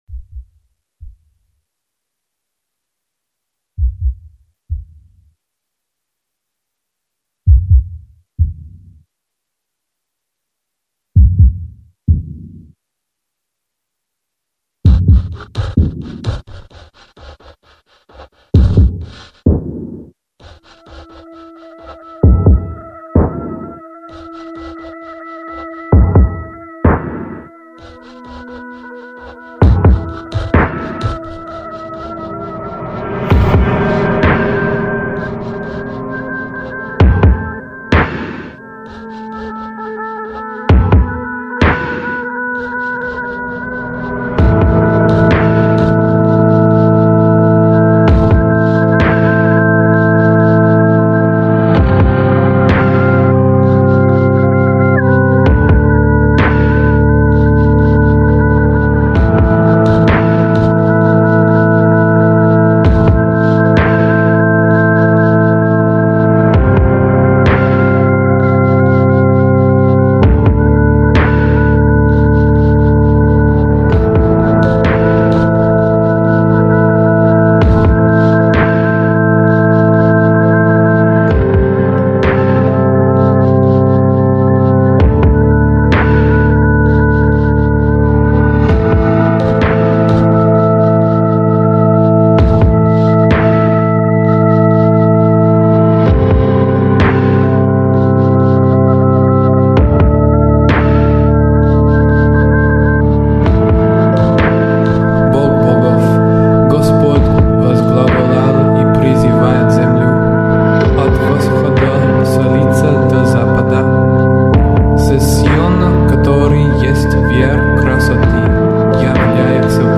Категория: Rock, Alternative